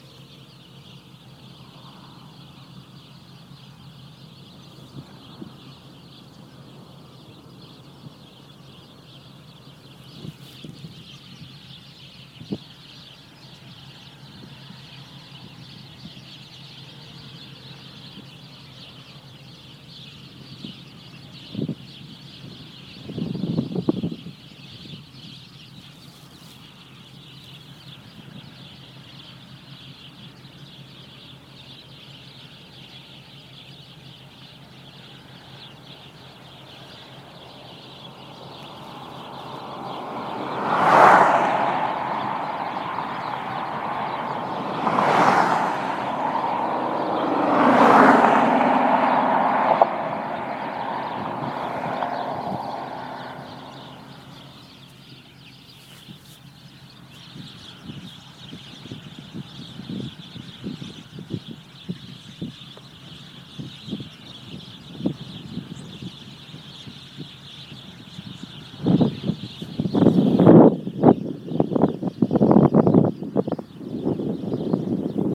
10_23_11-2pm-whistling_cables – click to play/download